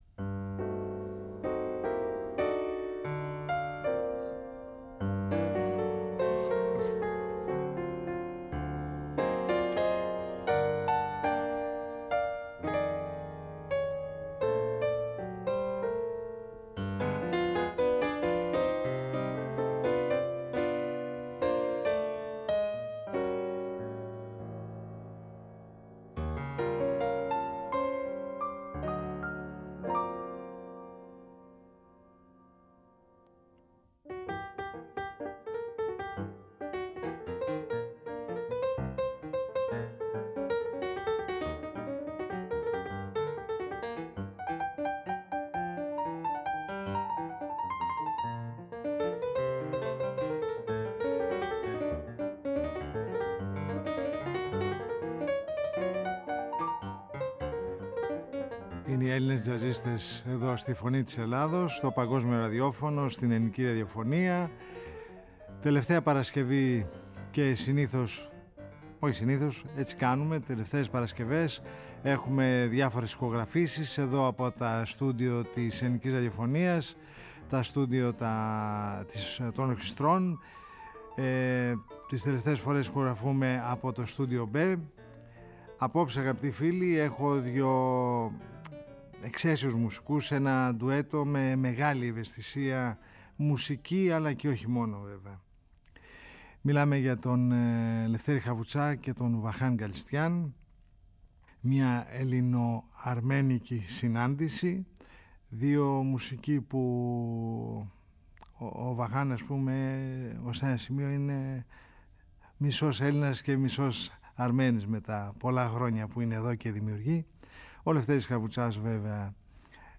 κιθαρίστα